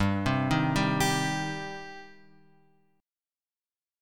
G Suspended 4th Sharp 5th